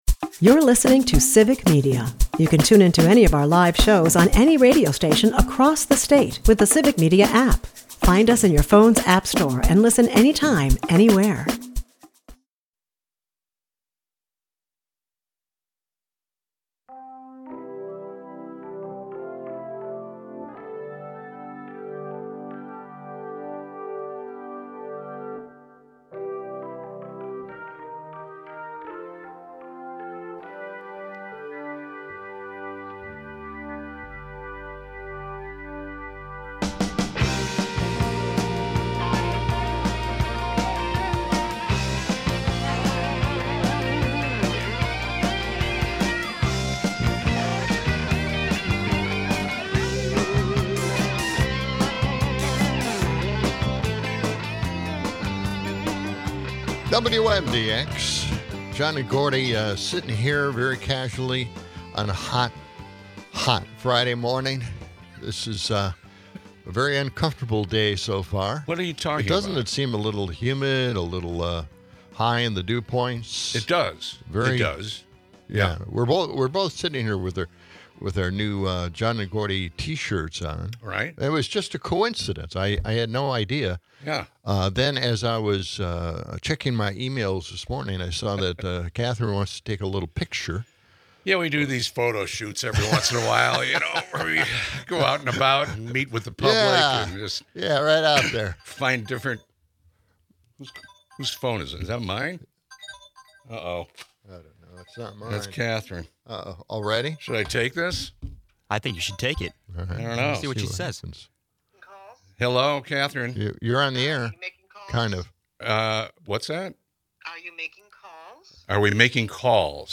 They dive into political chaos, displaying Governor Tim Walz's bold stance against Trump, while Trump and Elon get bullied (serves them right). The episode is peppered with humorous banter, insightful political commentary, and a touch of chaos, making it an entertaining listen.